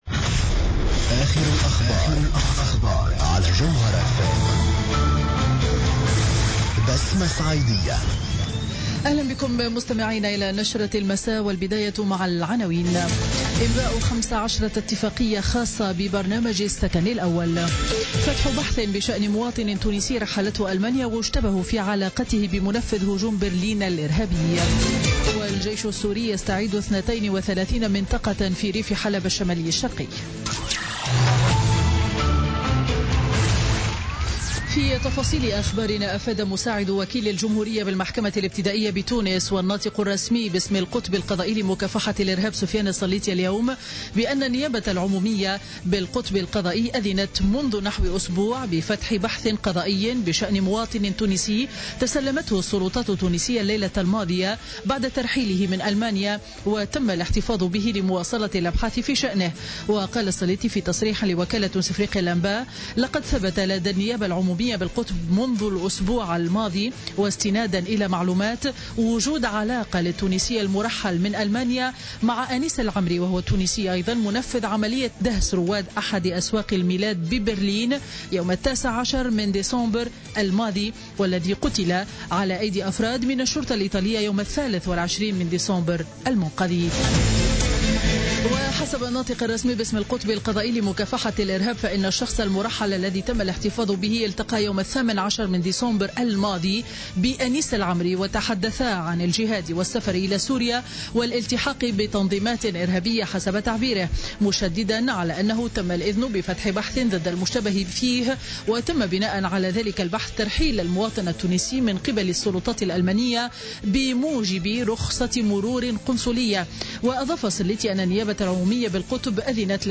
نشرة أخبار السابعة مساء ليوم الخميس 2 فيفري 2017